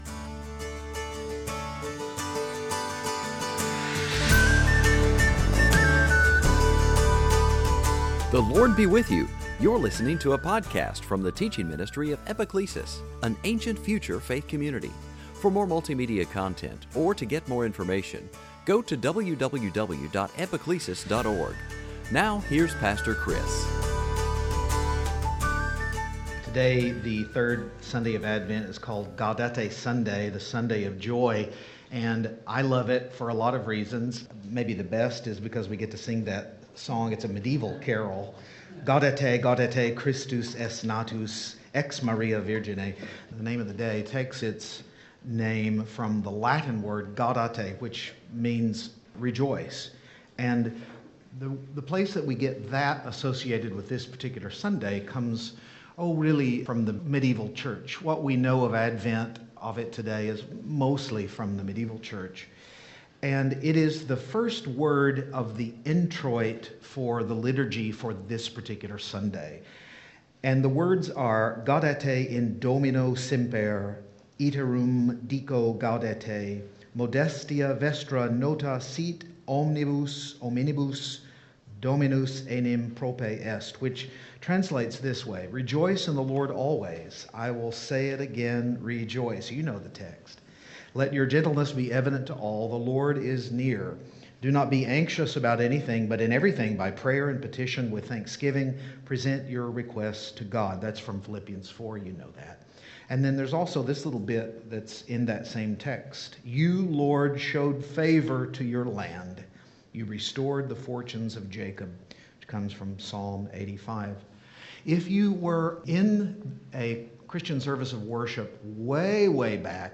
Along the way we listened to J.S. Bach's Jesu, Joy of Man's Desiring, too a brief look at Psalm 16, and finally landed on the Magnificat of Mary.
2022 Sunday Teaching Advent Gaudete George MacDonald Jesus Joy joy Magnificat rejoice Advent